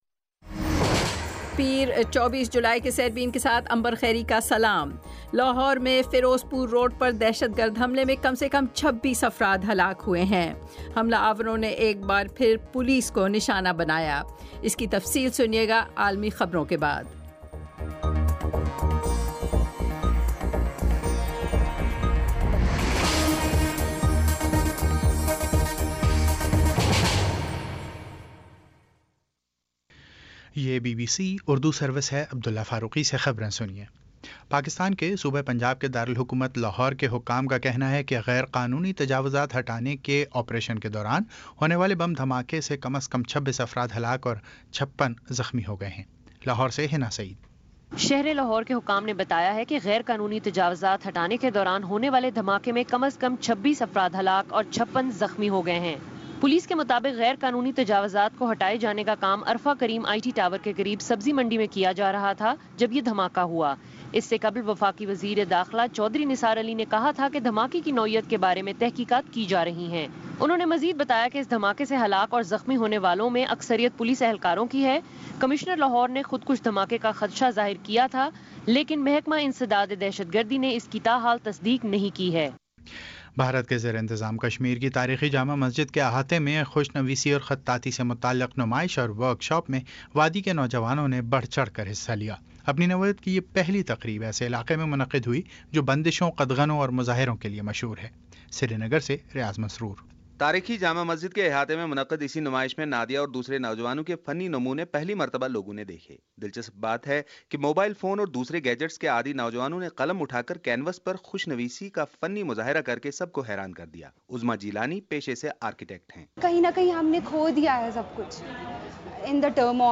پیر 24 جولائی کا سیربین ریڈیو پروگرام